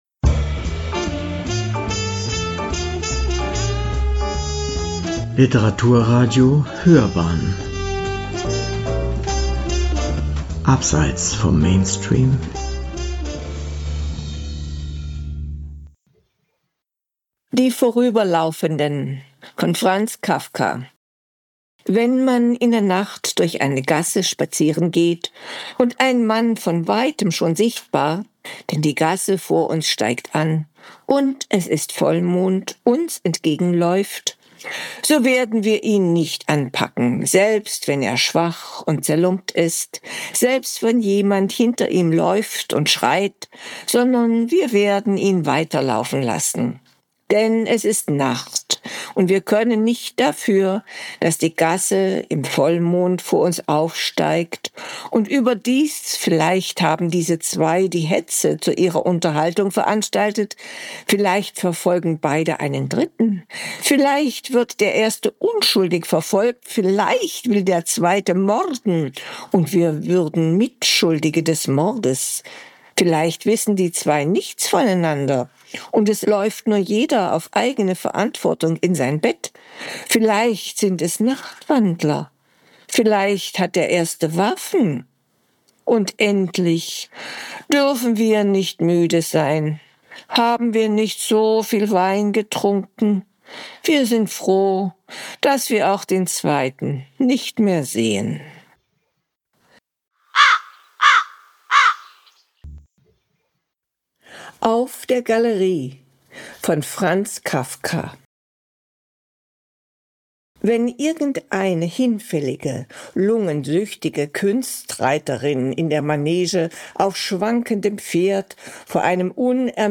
Künstlerisch verfremdet mit Stylar